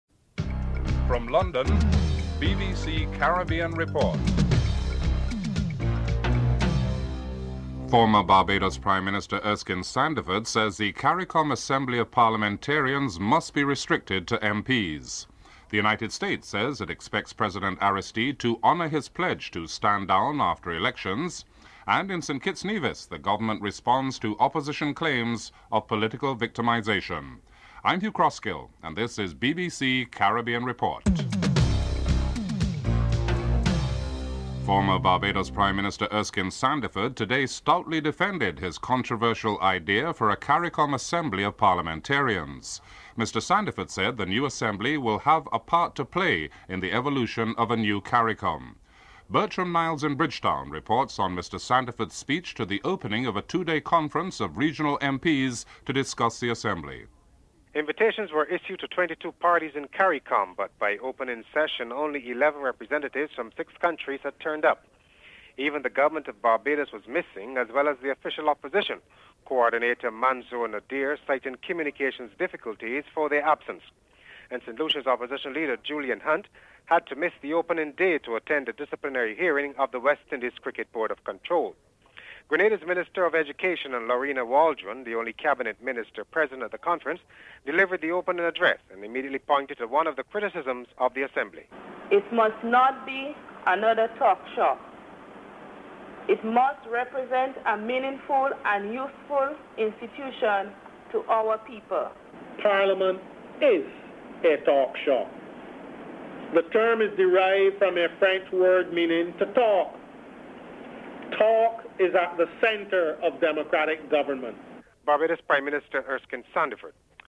1. Headlines
8. Recap of top stories (14:56-15:20)